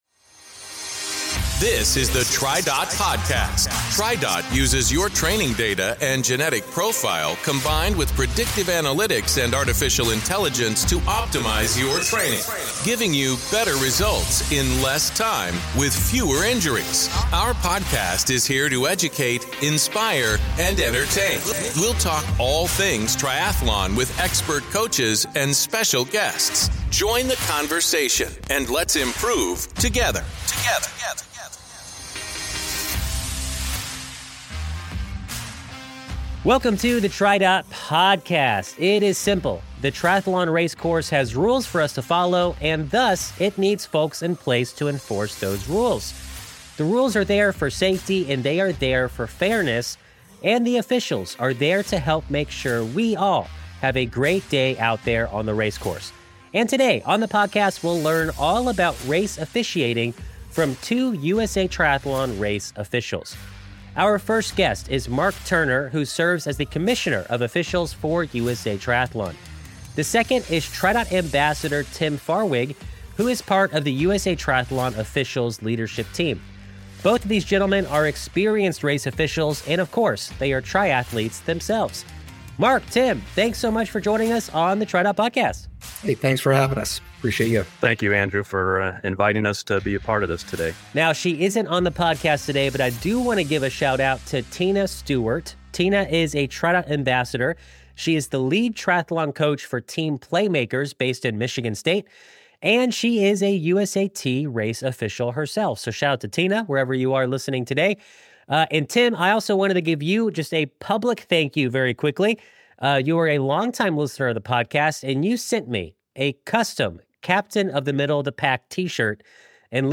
On this podcast, our guests, two USA Triathlon officials, will discuss the various roles and responsibilities of the officiating team.